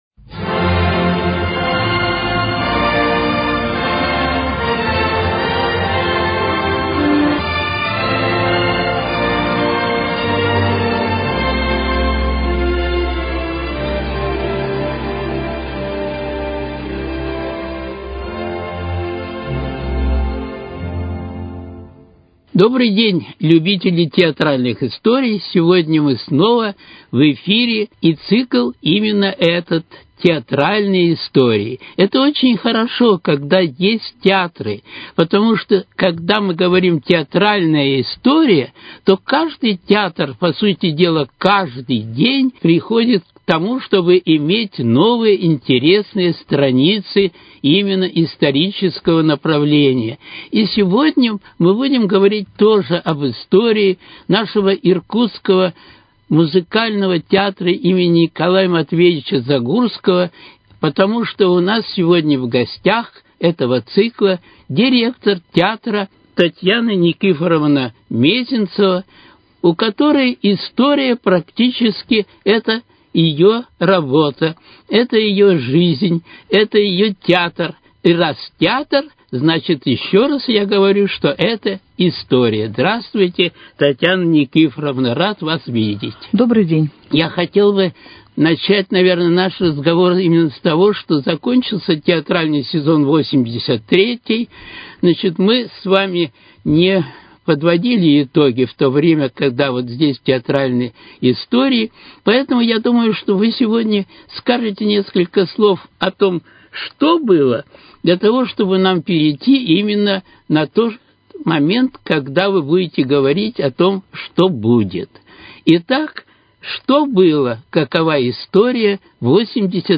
Театральные истории: Беседа